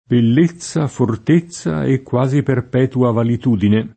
valetudine [ valet 2 dine ]